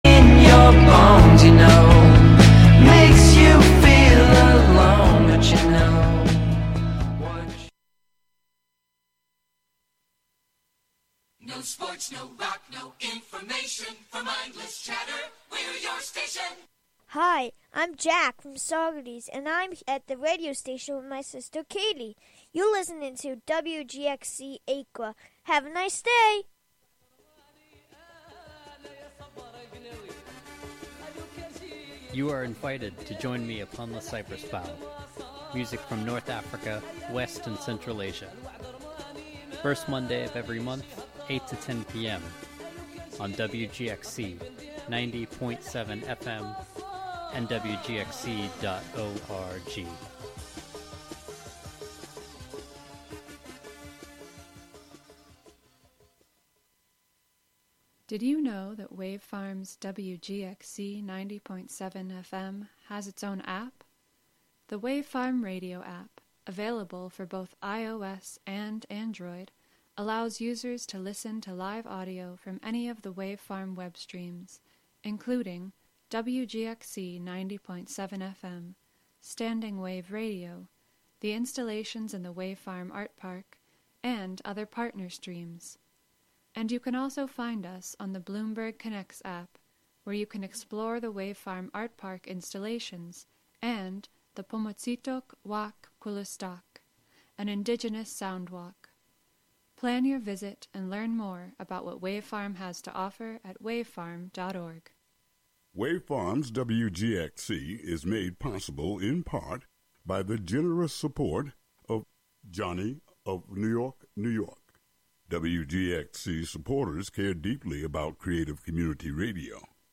These features offer listeners real, raw, and authentic conversations.